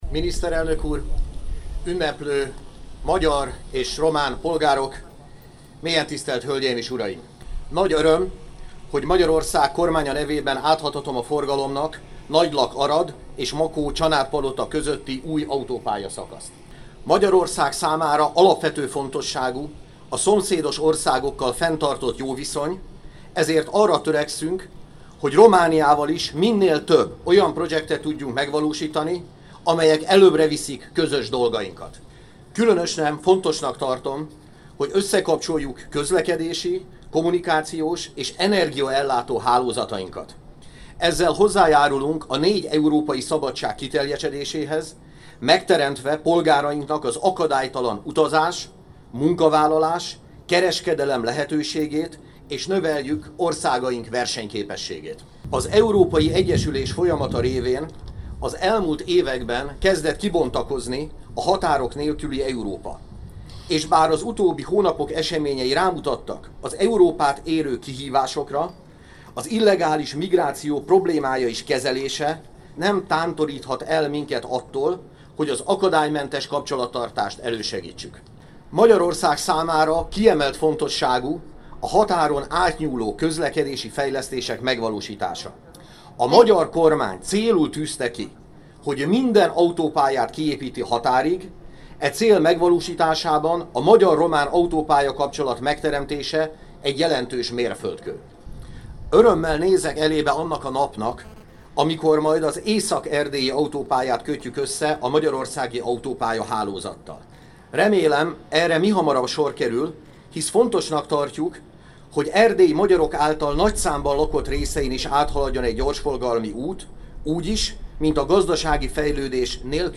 semjen_zsolt_beszede_az_autopalyaavaton.mp3